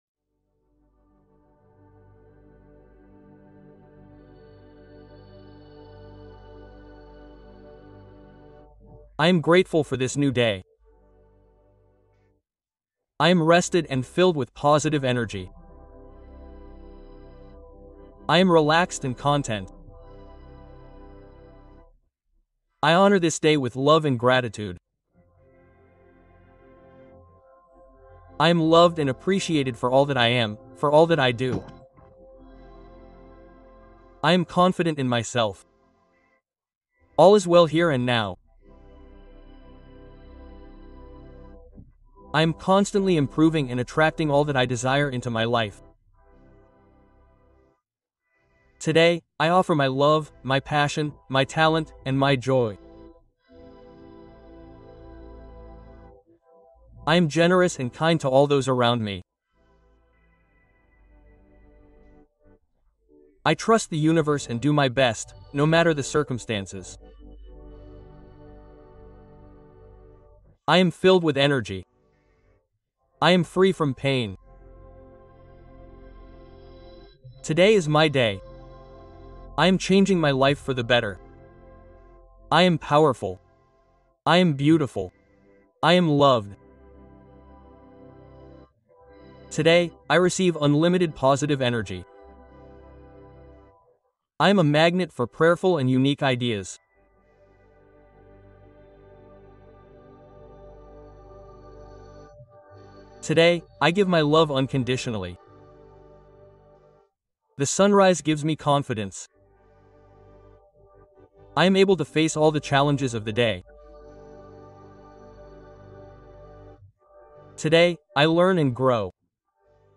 Méditation guidée pour transformer ton énergie et changer ta vie